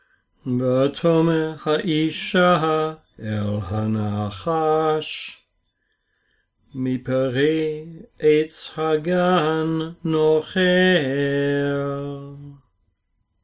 sing them with me.